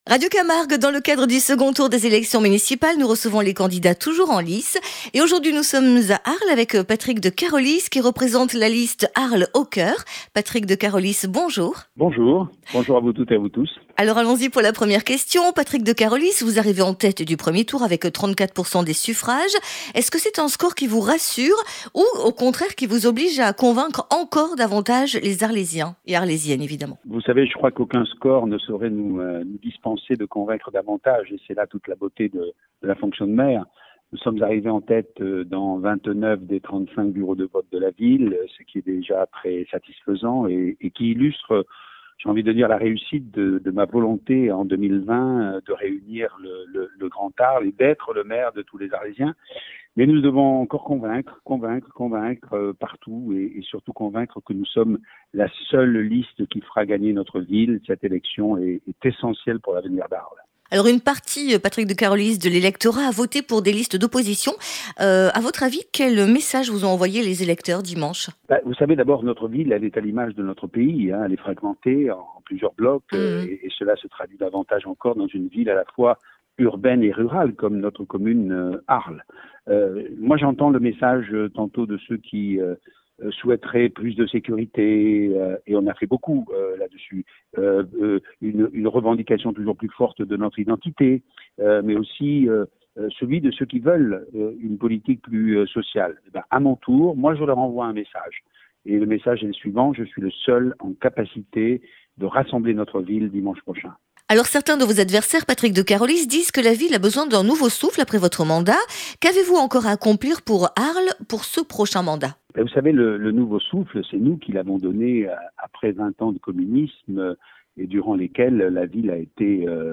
Municipales 2026 : entretien avec Patrick de Carolis